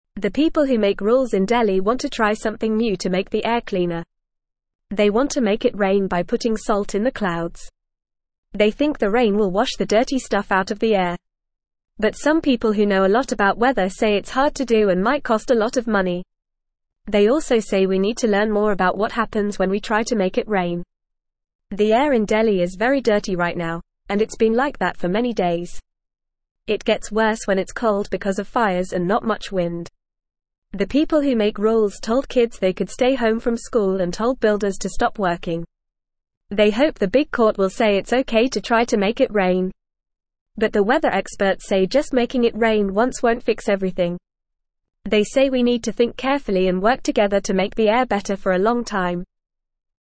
Fast
English-Newsroom-Beginner-FAST-Reading-Making-Rain-to-Clean-Delhis-Dirty-Air.mp3